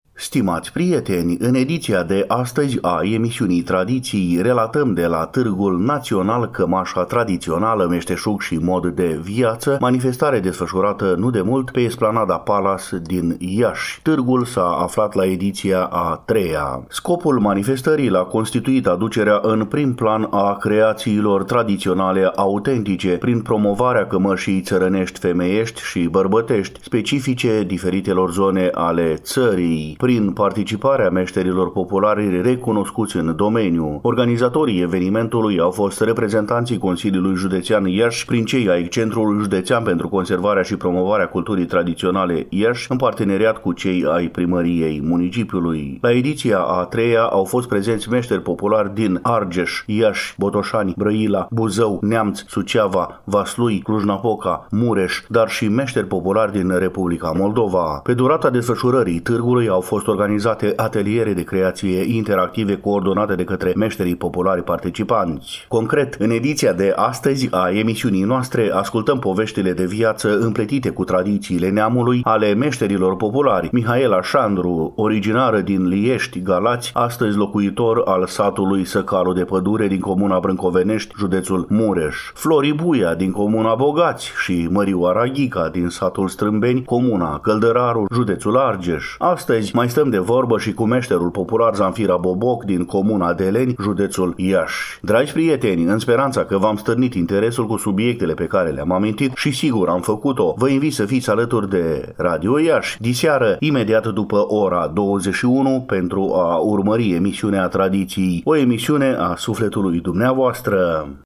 Stimați prieteni, în ediția de astăzi a emisiunii Tradiții, relatăm de la cea de a III-a ediție a Târgului național „Cămașa tradițională – meșteșug și mod de viață”, manifestare desfășurată, nu demult, pe Esplanada Palas din Iași.